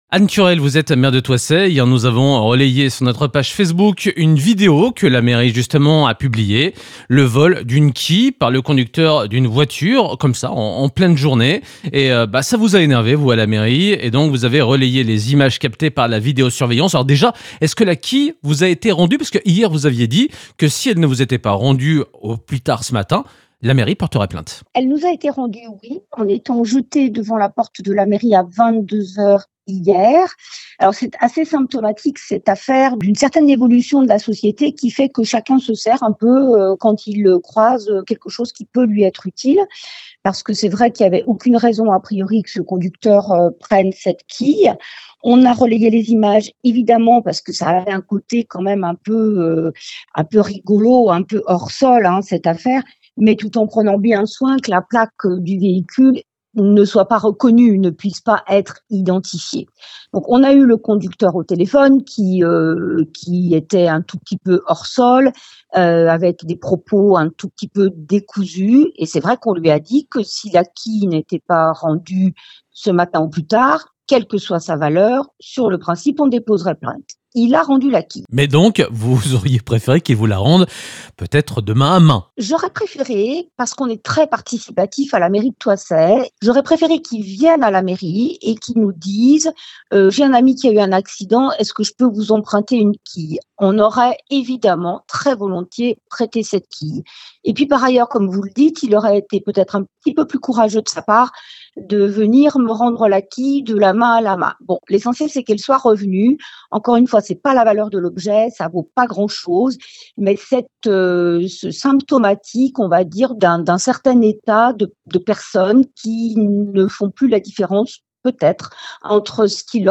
3. Interview de la Rédaction
Écoutez Anne Turrel, maire de Thoissey, revenir sur cette incivilité et expliquer si l’objet a été restitué et s’il y aura ou non dépôt de plainte.